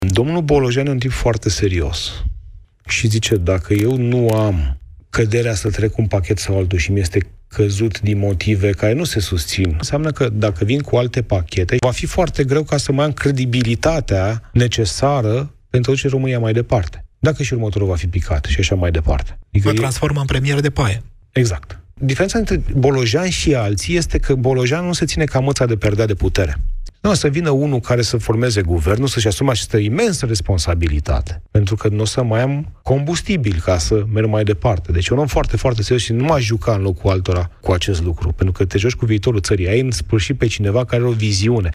Primarul Sectorului 6, Ciprian Ciucu, a explicat la Europa FM de ce ar fi posibilă o demisie a premierului Ilie Bolojan, în situația în care vreunul dintre Pachetele de reforme propuse ar fi respins la CCR sau în Parlament. Ar fi vorba, spune Ciprian Ciucu, de decredibilizarea întregului proces de reducere a deficitului bugetar.
Ciprian Ciucu, primarul Sectorului 6: Diferența dintre Bolojan și alții este că el nu se ține de putere ca măța de perdea